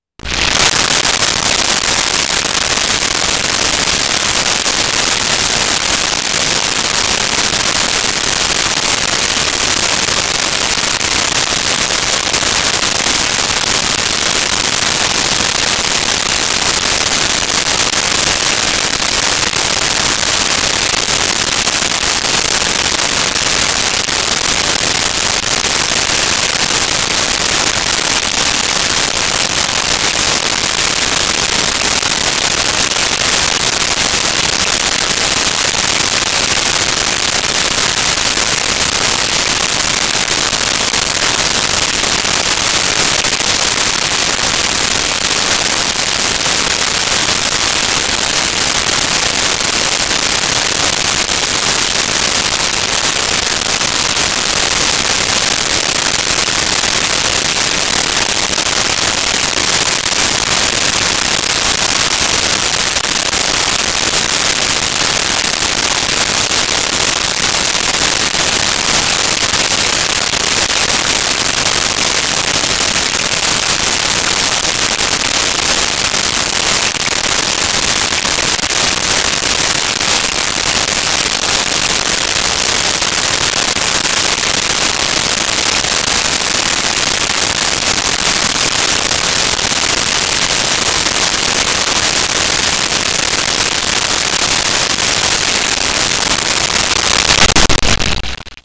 Cooking Pan Sizzle 01
Cooking Pan Sizzle 01.wav